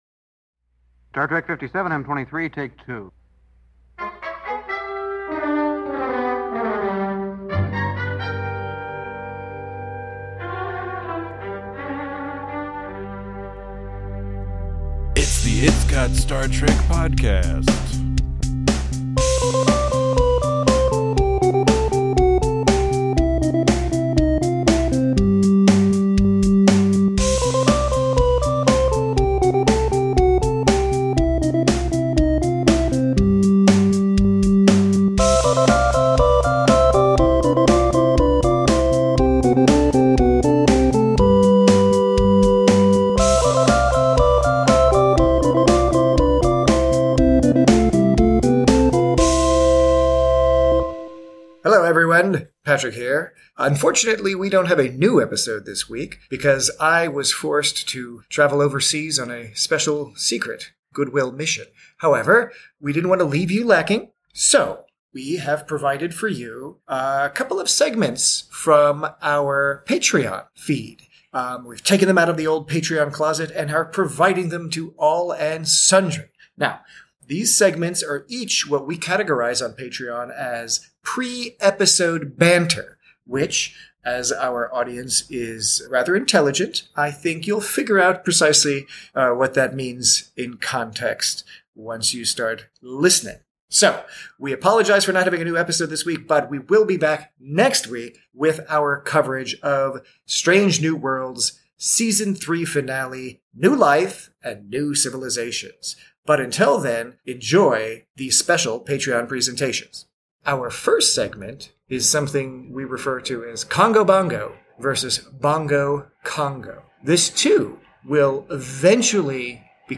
We welcome you to enjoy not one, but two "pre-episode banter" segments (i.e., the madness that goes on behind the scenes as we prep for that week's podcast).